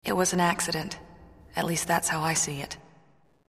Voice 1 - Voice 2 -
Sex: Female